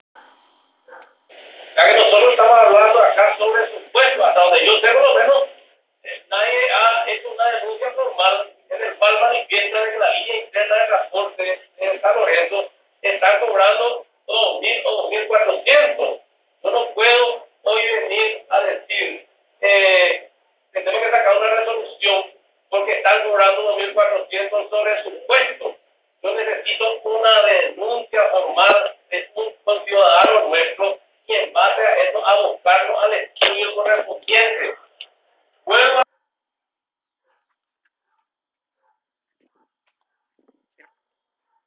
La acción es en respuesta a un concejal que pidió denuncia formal de un ciudadano donde conste que el pasaje en los internos de pasajeros se cobra G 2.400. [/box]PARTE DEL AUDIO DE LO QUE DIJO EL CONCEJAL IGNACIO BRÍTEZ
En sesión del miércoles pasado en sesion de la Junta Municipal el concejal Ignacio Brítez (ANR), dijo que necesita de una denuncia formal de un ciudadano en la cual conste que los internos de pasajeros están cobrando G 2.400 para poder accionar, “Nosotros estamos hablando acá de un “supuesto”, hasta donde yo sé por lo menos nadie ha hecho una denuncia formal en el cual manifiesta que de que las líneas internas de transporte en San Lorenzo están cobrando 2 mil o 2.400, yo no puedo hoy venir a decir tenemos que sacar una resolución porque están cobrando 2400 sobre supuesto yo necesito una denuncia formal de un conciudadano nuestro y en base a eso abocarnos al estudio correspondiente”.